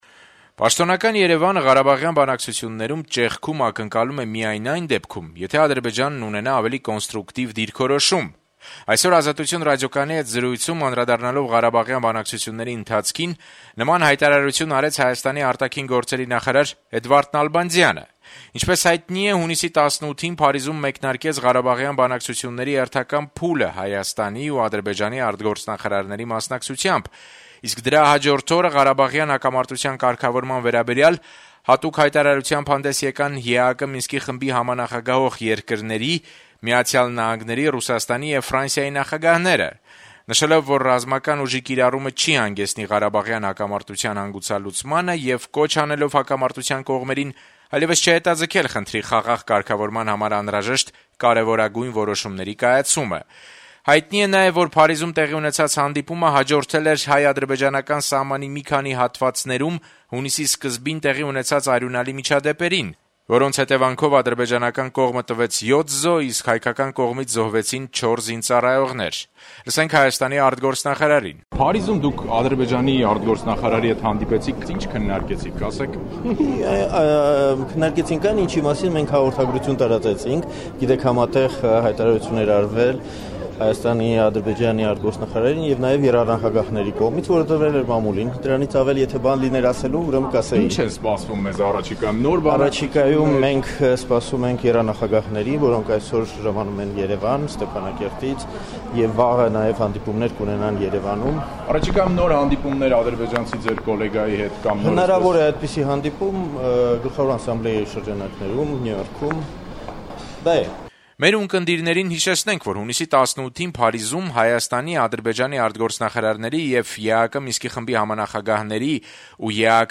Պաշտոնական Երեւանը ղարաբաղյան բանակցություններում «ճեղքում» ակնկալում է միայն այն դեպքում, եթե Ադրբեջանն ունենա ավելի կոնստրուկտիվ դիրքորոշում: Այսօր «Ազատություն» ռադիոկայանի հետ զրույցում անդրադառնալով ղարաբաղյան բանակցությունների ընթացքին` նման հայտարարություն արեց Հայաստանի արտաքին գործերի նախարար Էդվարդ Նալբանդյանը:
Էդվարդ Նալբանդյանի հետ այսօր մեզ հաջողվեց զրուցել Երեւանում տեղի ունեցած «Հայաստան-Եվրոպայի խորհուրդ 2012-2014 թթ. գործողությունների ծրագրի մեկնարկային կոֆերանսի» ժամանակ: